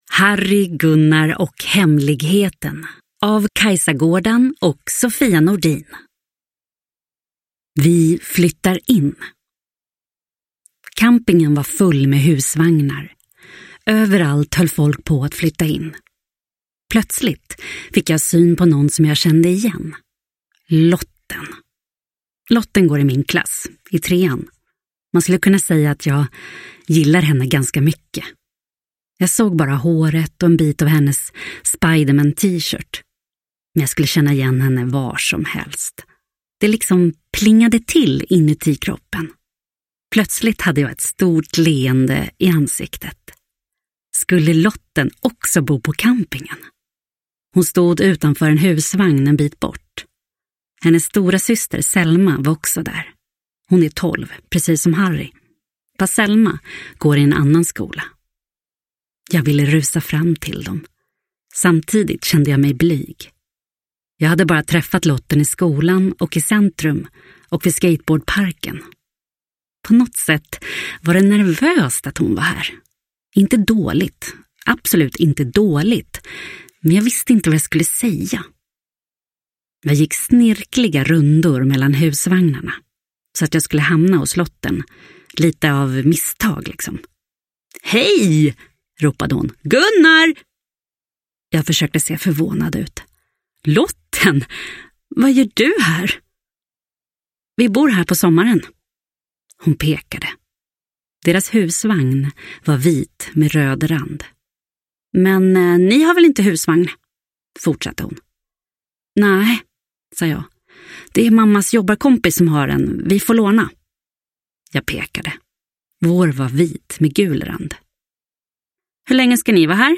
Harry, Gunnar och hemligheten – Ljudbok – Laddas ner